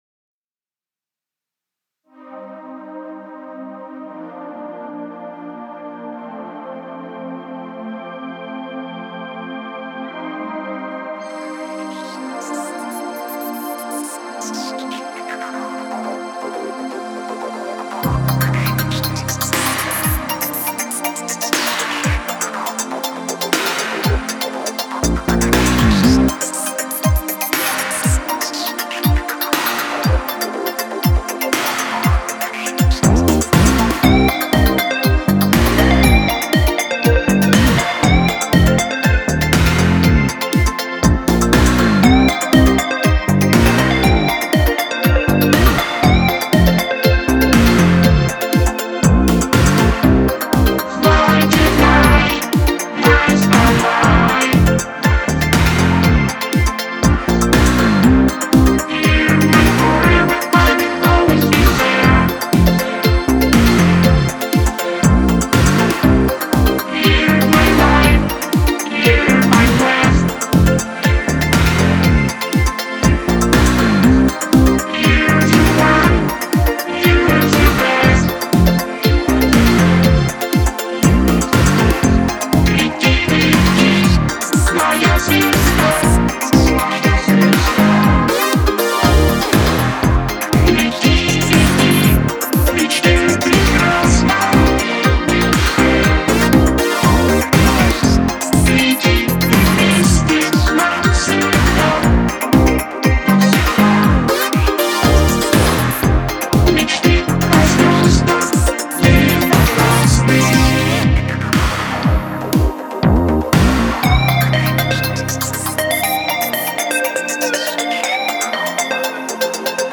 Правда я не парился, бо вакодер и... не для сцены.
Forever (marsh-pop